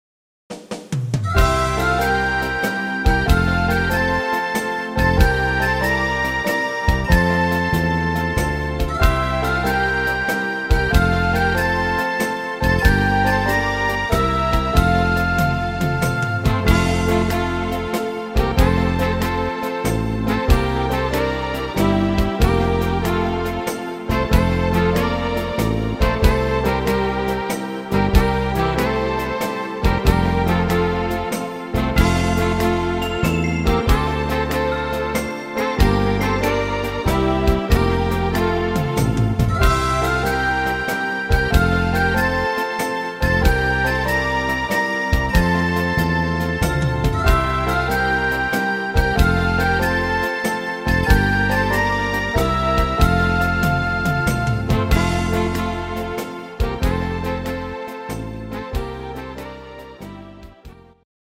im Schlagersound